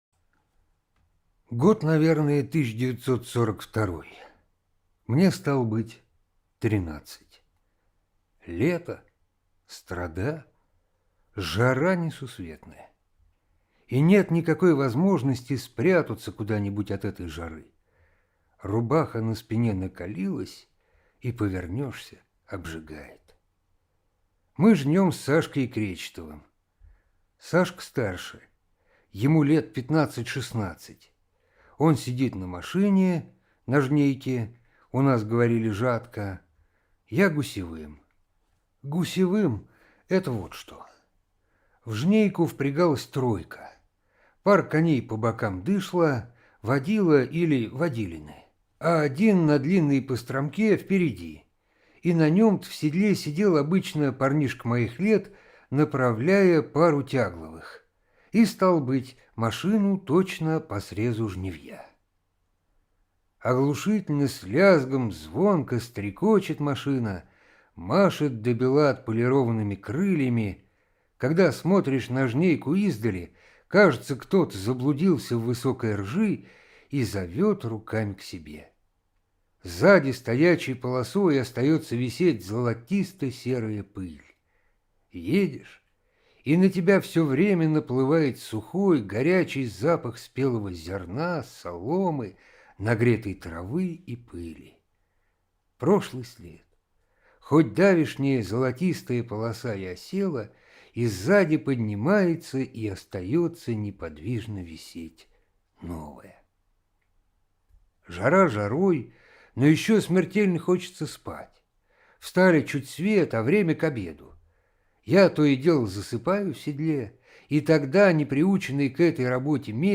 Жатва - аудио рассказ Шукшина В.М. Рассказ про жаркий летний день в 1942 году. Все мужчины ушли на войну и жать хлеб приходилось подросткам.